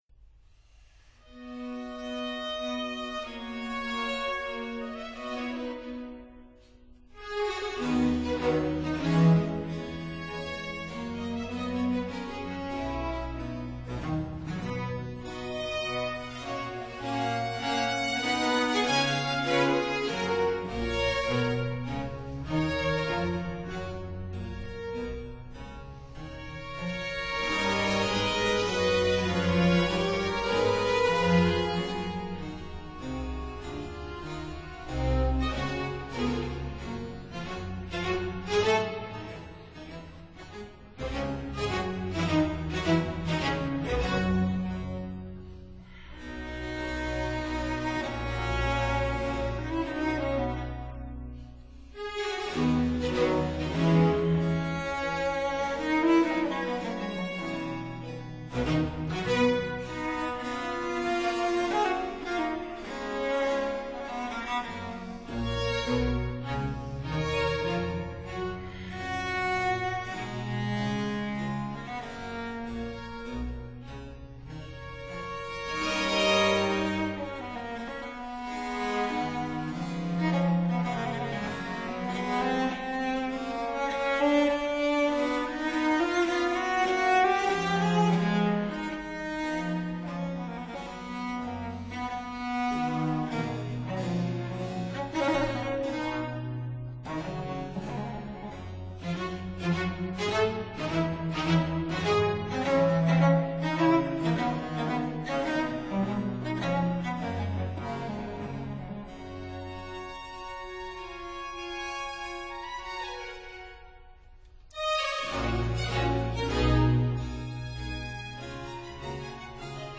類型： 古典
•(01) Symphony in E flat major, Wq. 179
•(04) Harpsichord Concerto in C major, Wq. 20
•(10) Cello Concerto No. 1 in A minor, Wq. 170
harpsichord
cello